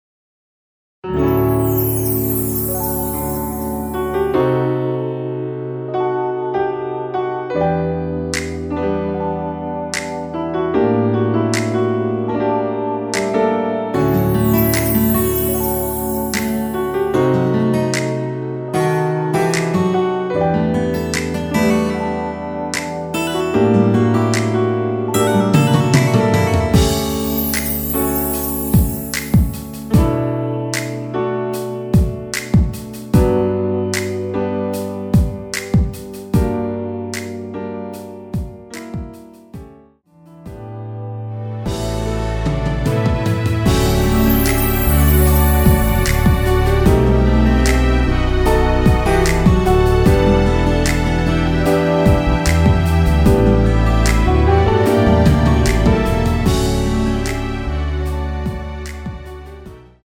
원키에서 (-2)내린 MR 입니다.
앞부분30초, 뒷부분30초씩 편집해서 올려 드리고 있습니다.
곡명 옆 (-1)은 반음 내림, (+1)은 반음 올림 입니다.